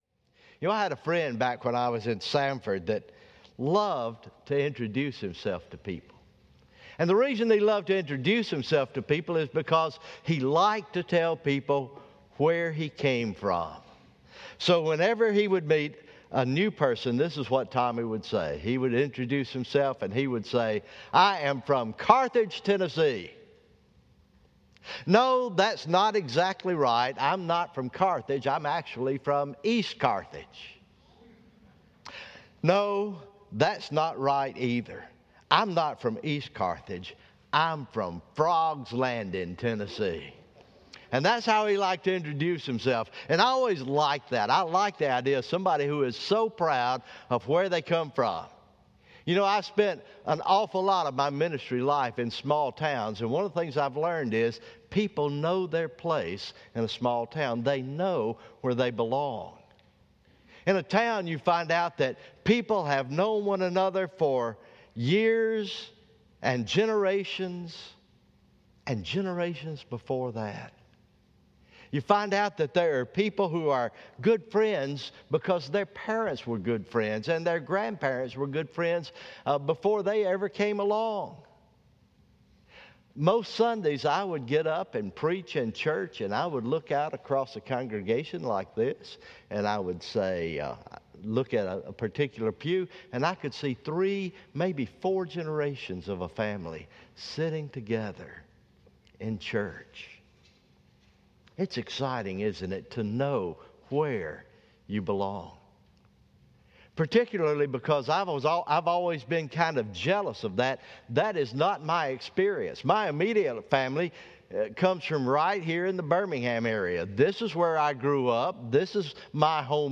October 4, 2020 Morning Worship